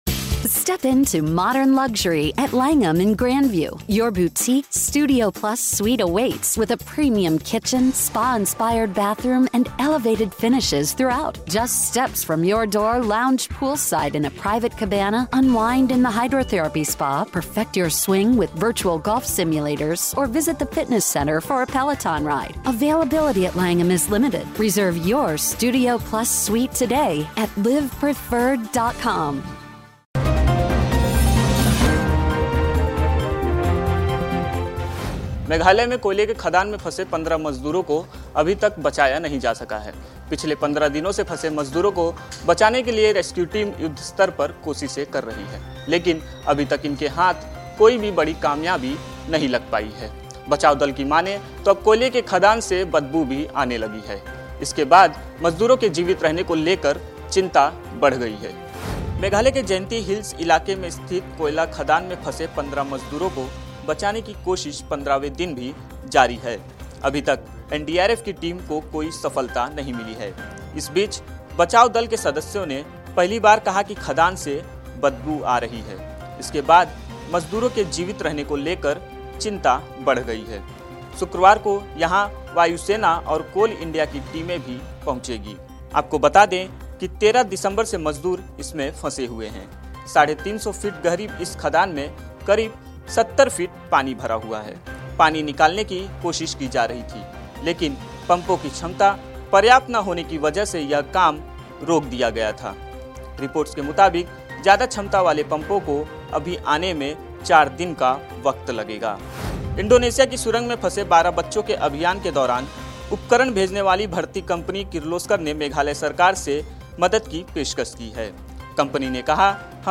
न्यूज़ रिपोर्ट - News Report Hindi / मेघालय में कोयले की खदान में फंसी है 15 जिंदगियां, आखिर कैसे बचेगी इनकी जान ?